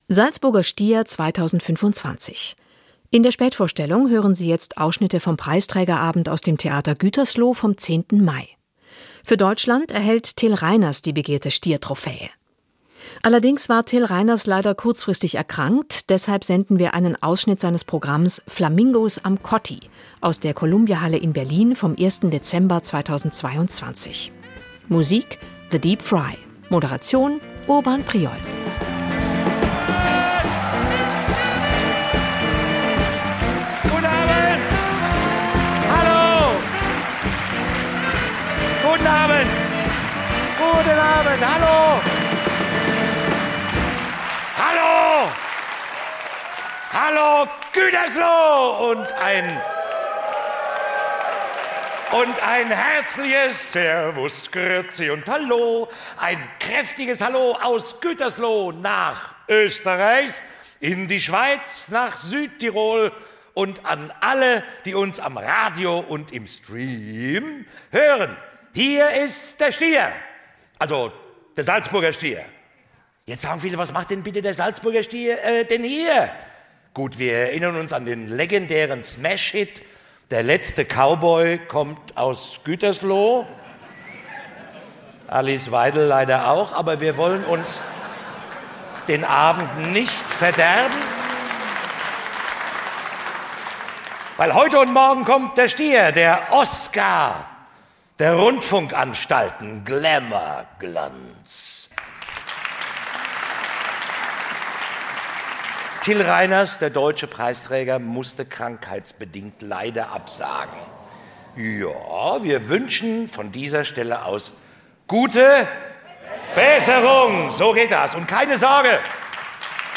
Moderation: Urban Priol - Aufzeichnung vom 9. und 10. Mai 2025 im Theater Gütersloh